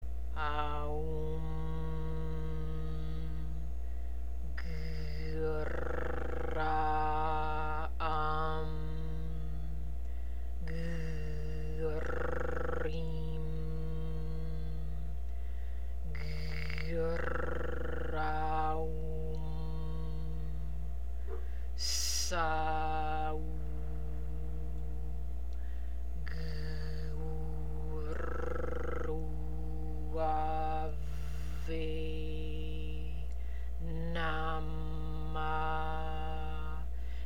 МАНТРА ЗА ЮПИТЕР:
Произношение: AАА-УУУ-MMM ٠ ГГ-РР-AА- AА-MM ٠ ГГ-РР-ИИ-MM ٠ ГГ-РР-AА-УУ-MM ٠ ССА-УУУ ٠ ГГ-УУУ-РР-УУУ-АА-ВЕЙ ٠ НАА-MAА
"Г" се вибрира в задната част на гърлото (силно гърлено).
6 - Jupiter Square Mantra.mp3